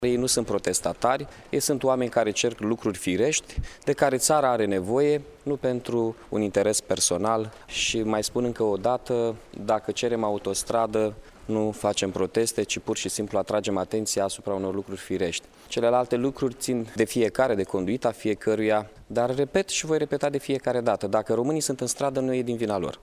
Primarul Iaşului, Mihai Chirica a subliniat faptul că în Piaţa Unirii nu a fost vorba de un protest ci oamenii atrăgeau atenţia asupra unui lucru necesar: